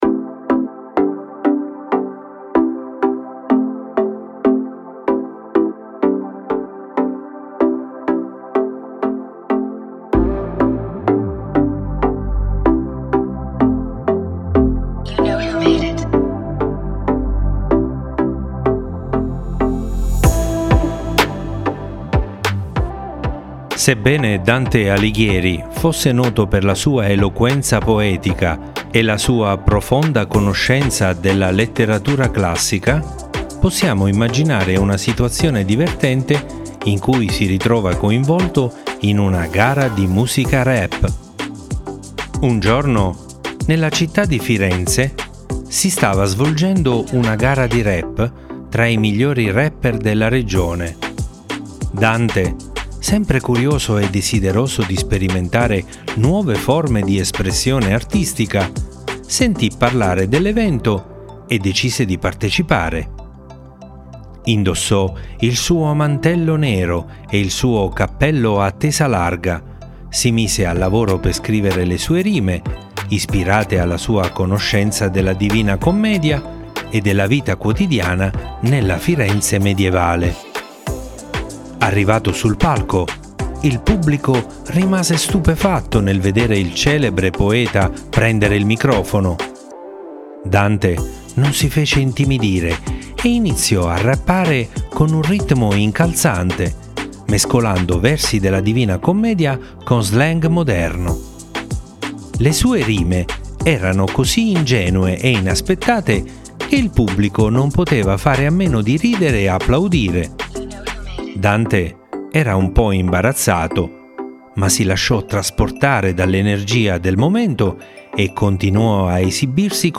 Leggi e ascolta la storia di Dante in versione rap
dante-in-versione-rap